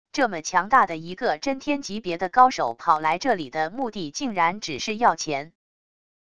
这么强大的一个臻天级别的高手跑来这里的目的竟然只是要钱wav音频生成系统WAV Audio Player